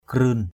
/krɯ:n/